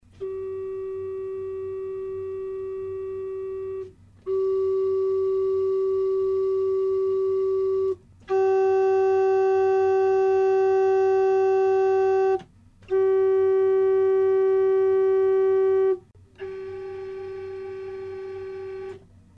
Ecouter successivement 5 tuyaux donnant la même note: un Bourdon (en bois), une flûte à cheminée, un prestant ("Principal"), une flûte à fuseau (Flageolet avec "biseau" muni de dents), une flûte large (Quinte 1'1/3 pas très bien harmonisée!):